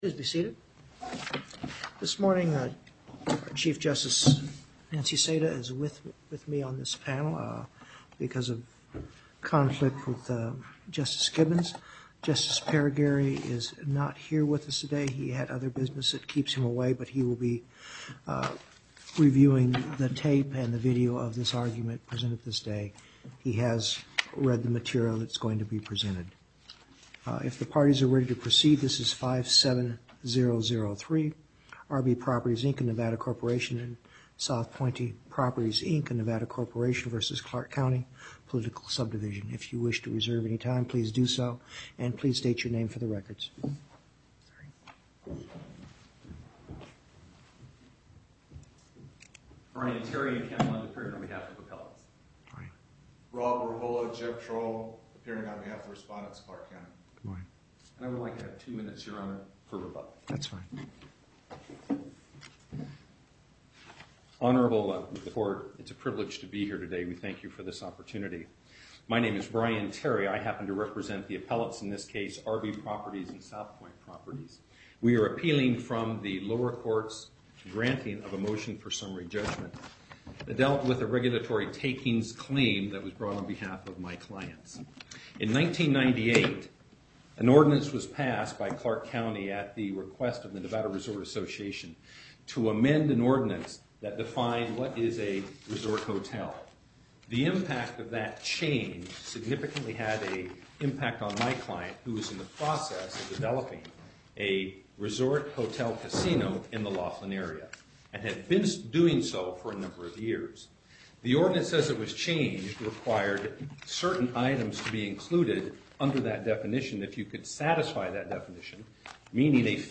Location: Las Vegas Southern Nevada Panel